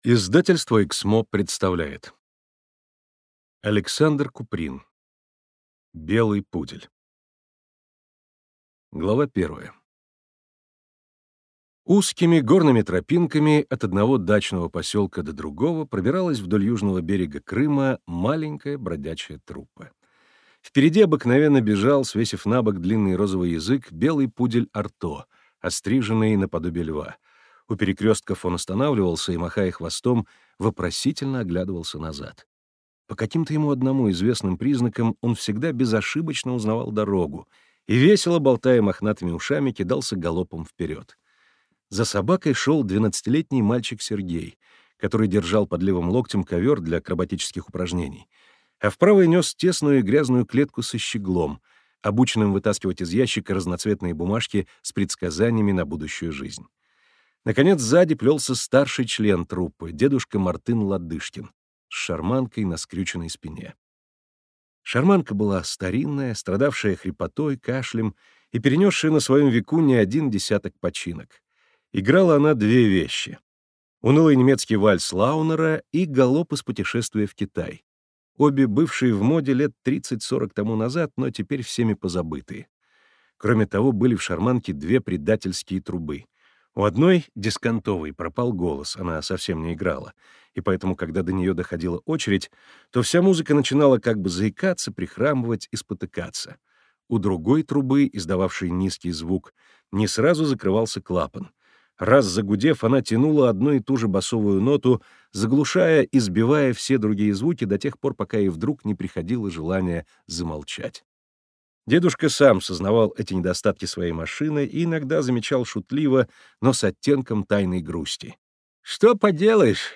Аудиокнига Белый пудель | Библиотека аудиокниг